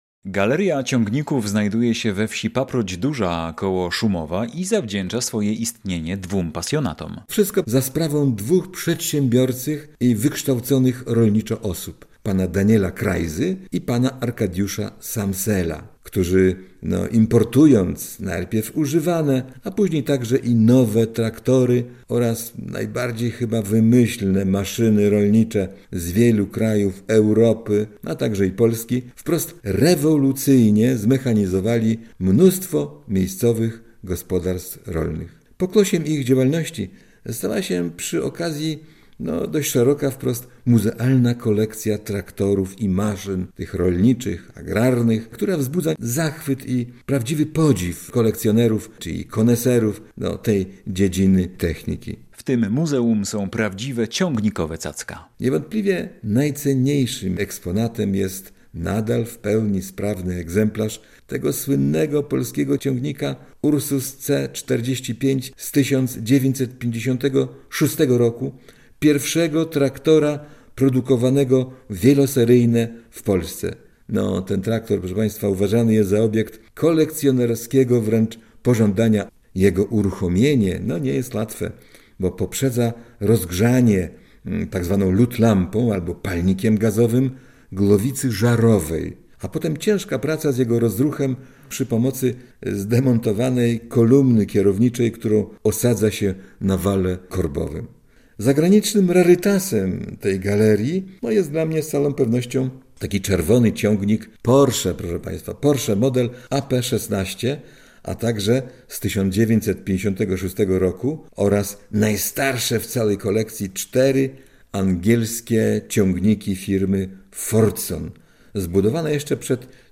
pasjonat podróży